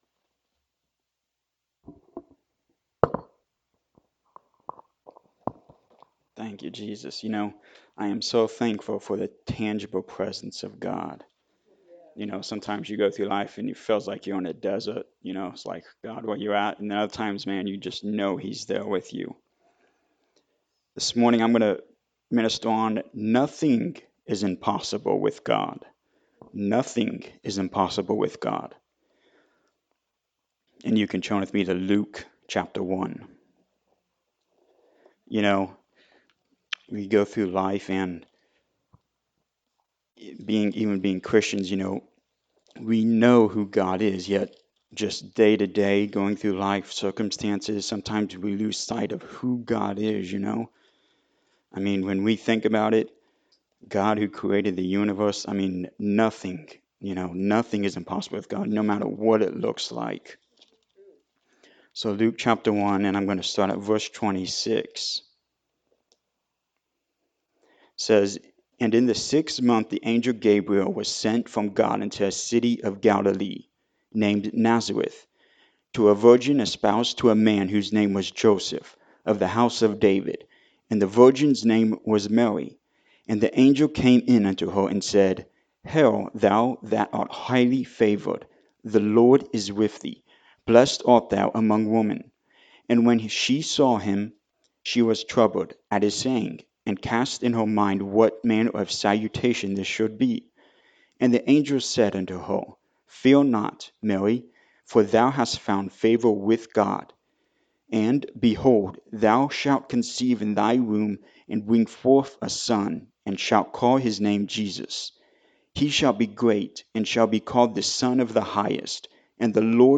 Luke 1:26-38 Service Type: Sunday Morning Service Sometimes we lose sight of Who God is.
Sunday-Sermon-for-December-3-2023.mp3